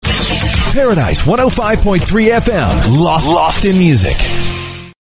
RADIO IMAGING / TOP 40